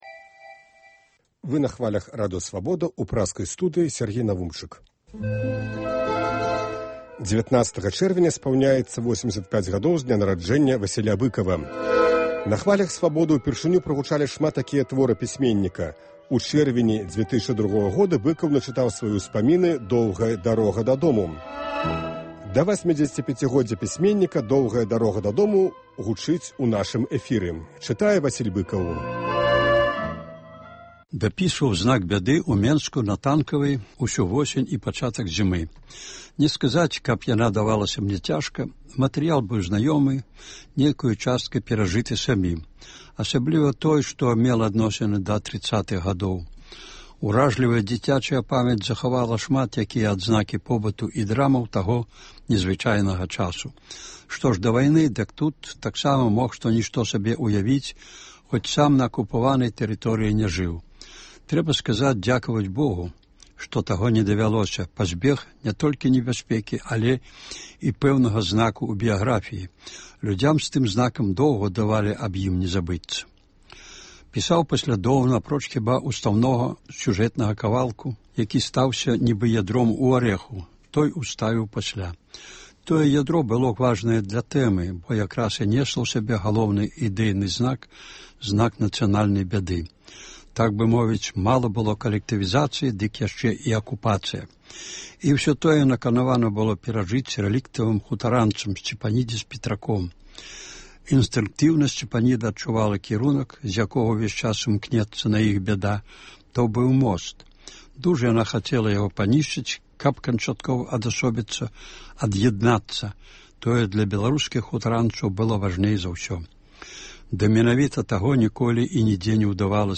19 чэрвеня спаўняецца 85 гадоў з дня нараджэньня Васіля Быкава. Сёлета ў чэрвені штодня ў нашым эфіры гучыць “Доўгая дарога дадому” ў аўтарскім чытаньні.